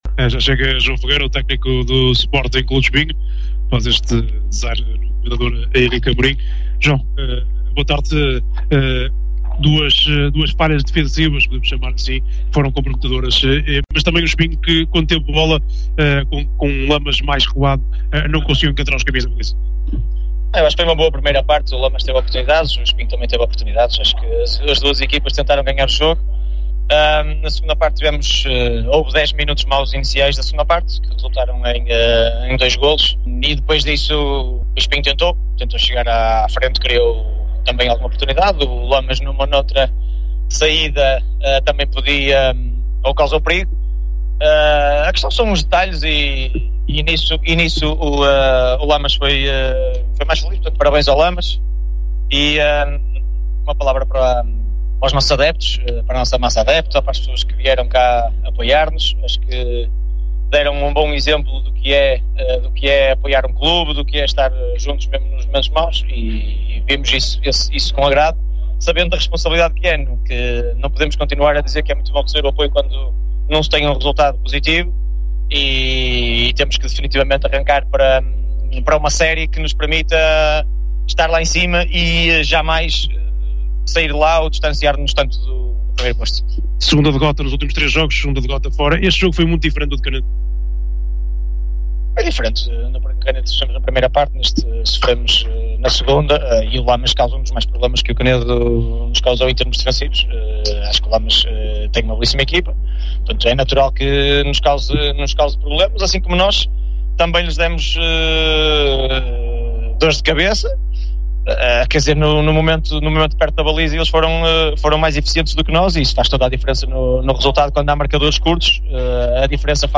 acompanhou o embate no Comendador Henrique Amorim e ouviu os técnicos no final.